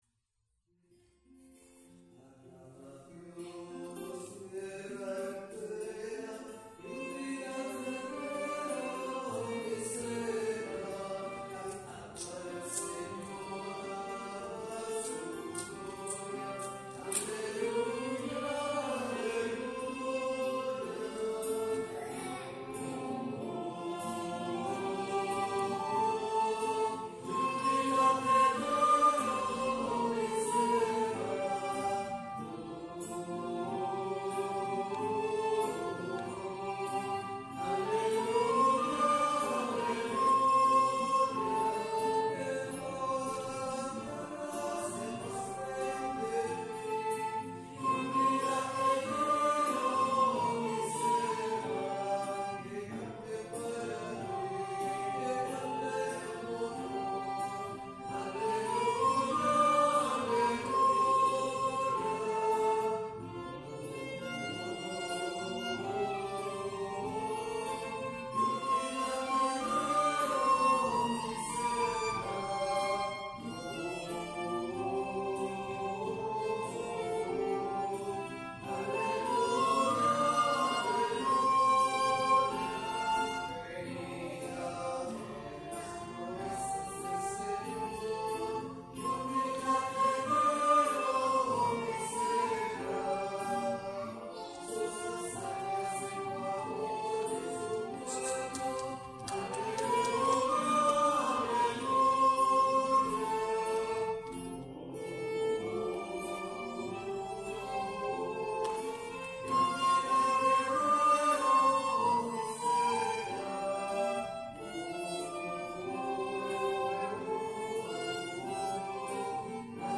Pregària de setembre
Pregària de Taizé a Mataró... des de febrer de 2001